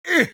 CosmicRageSounds / ogg / general / combat / creatures / default / he / attack2.ogg